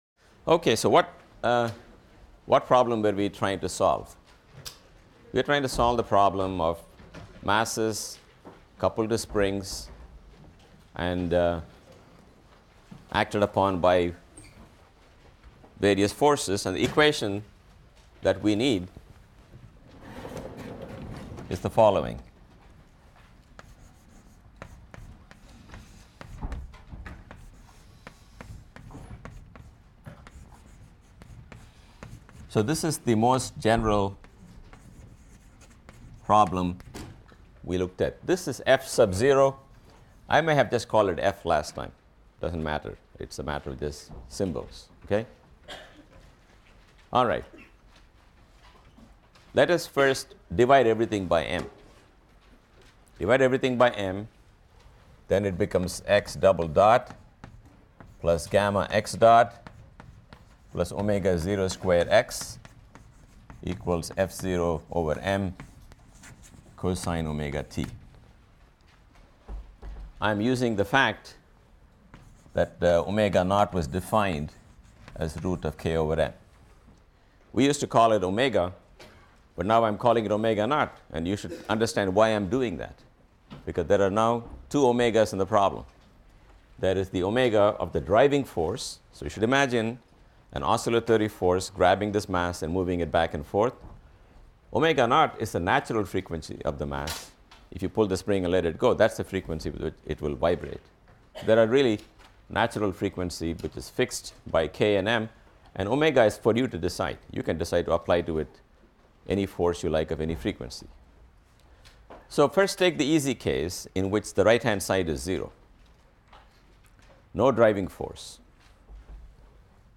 PHYS 200 - Lecture 18 - Simple Harmonic Motion (cont.) and Introduction to Waves | Open Yale Courses